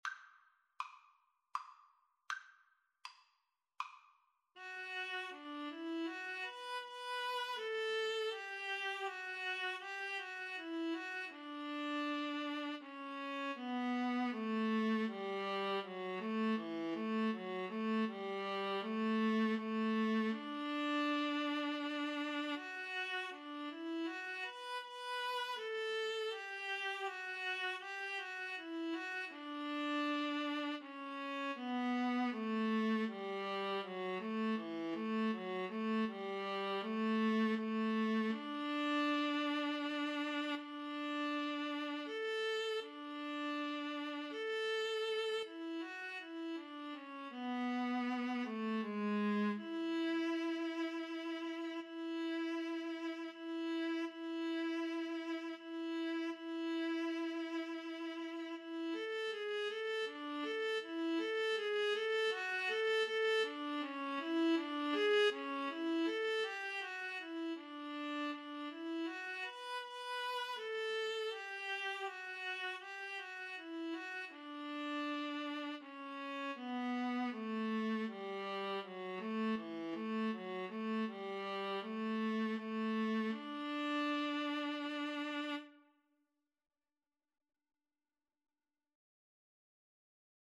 D major (Sounding Pitch) (View more D major Music for Viola Duet )
Andante
Viola Duet  (View more Easy Viola Duet Music)
Classical (View more Classical Viola Duet Music)